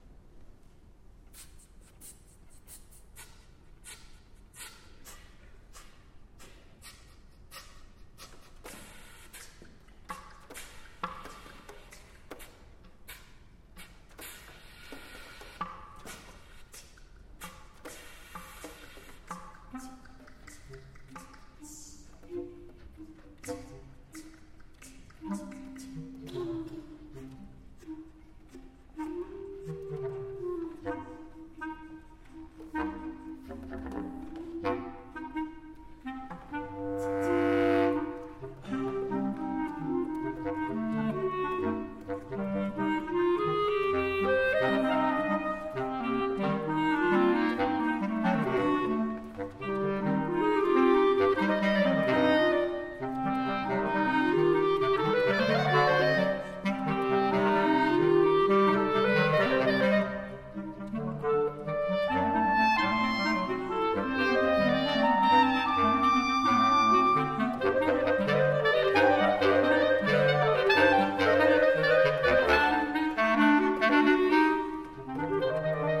Pour quatuor de clarinettes :
1ère et 2ème clarinettes
3ème clarinette (ou cor de basset)
et clarinette basse